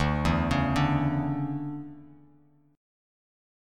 DM7sus2 chord